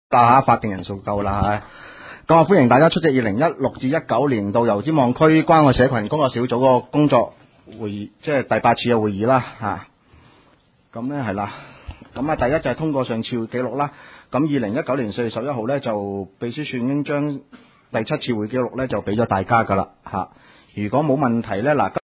工作小组会议的录音记录
关爱社群工作小组第八次会议 日期: 2019-04-25 (星期四) 时间: 下午2时30分 地点: 九龙旺角联运街30号 旺角政府合署4楼 油尖旺区议会会议室 议程 讨论时间 1. 通过上次会议记录 00:00:22 2.